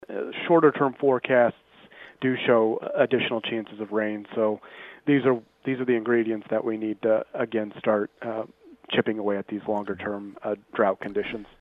State Climatologist Justin Glisan says the state needs several widespread rainfall events to dent the longer-term moisture deficits.